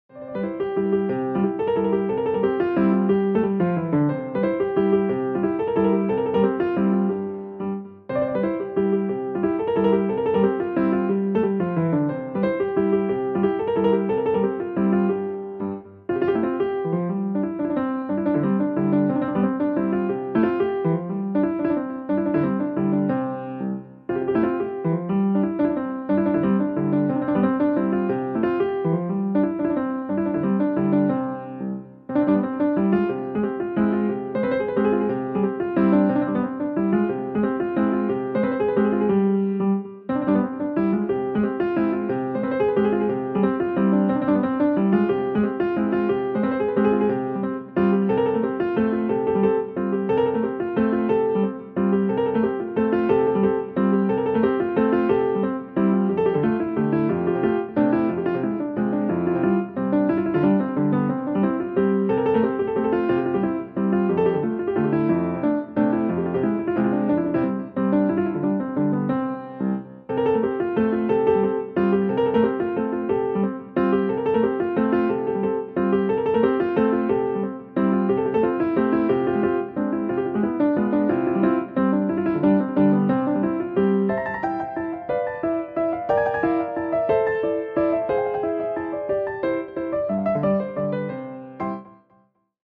Müəllif: Azərbaycan Xalq Rəqsi